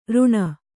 ♪ ruṇa